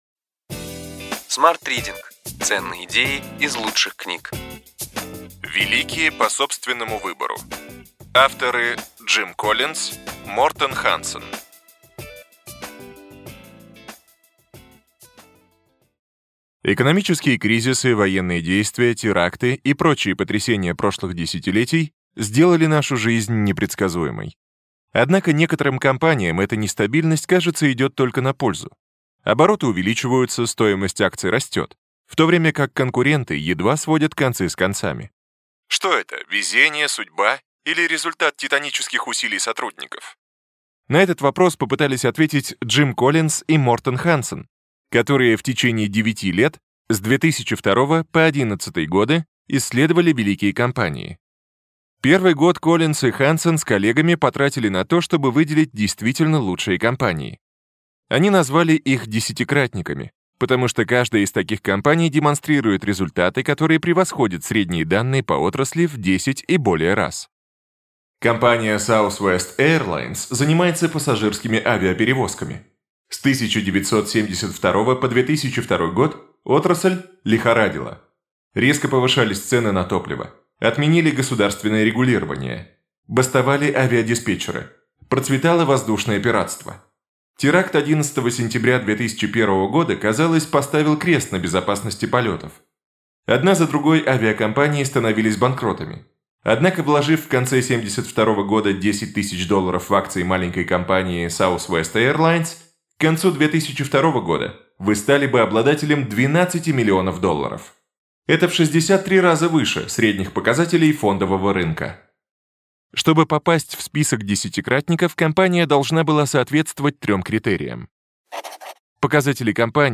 Аудиокнига Ключевые идеи книги: Великие по собственному выбору.